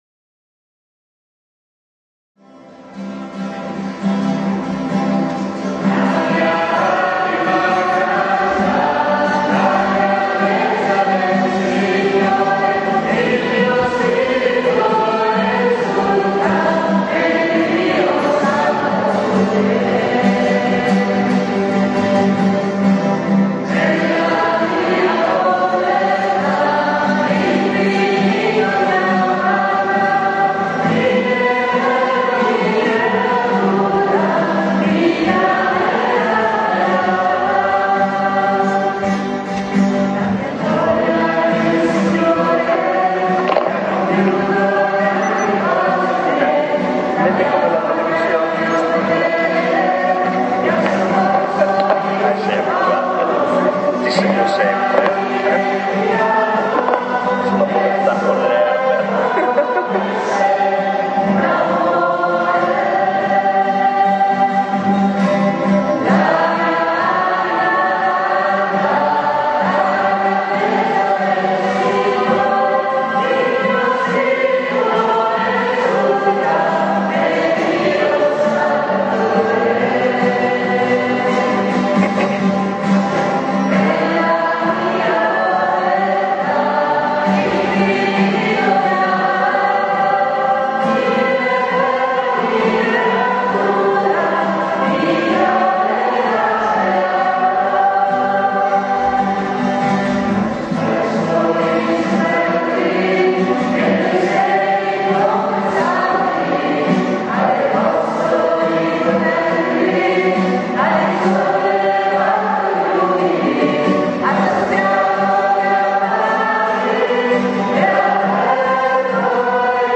Ritiro Spirituale Docmenica 25 febbraio 2007.